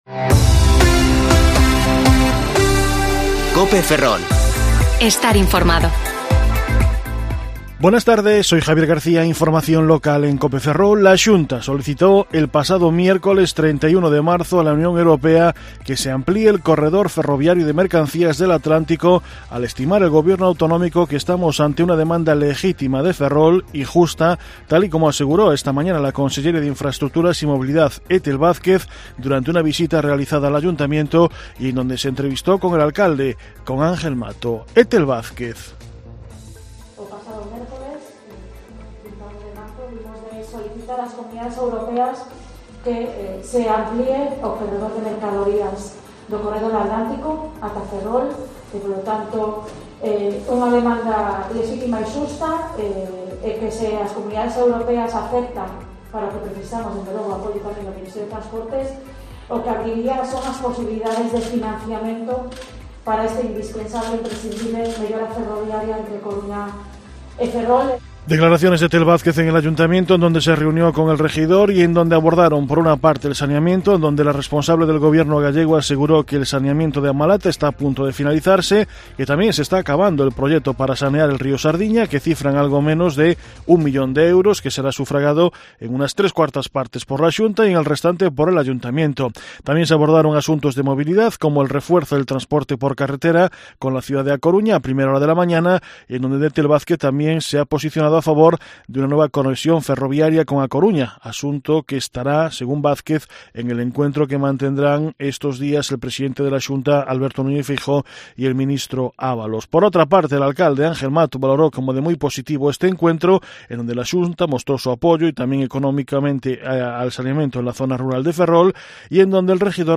Informativo Mediodía COPE Ferrol 7/4/2021 (De 14,20 a 14,30 horas)